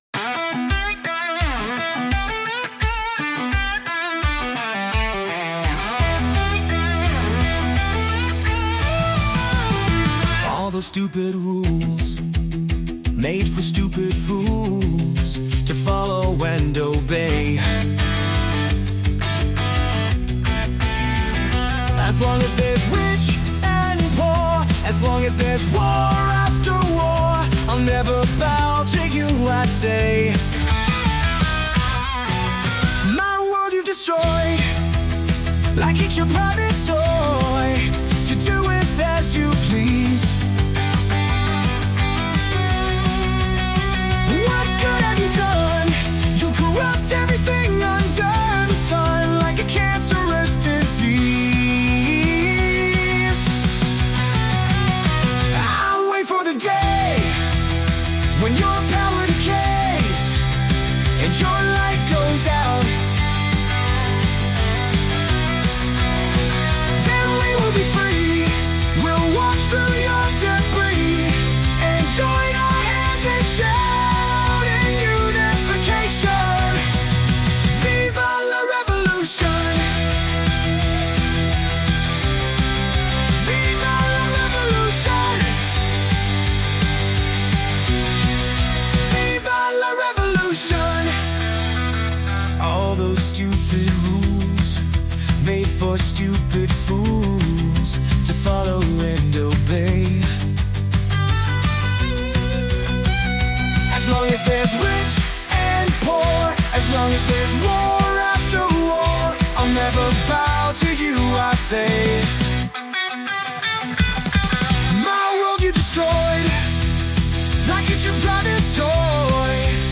rebellious and defiant anthem
With its raw energy and call to action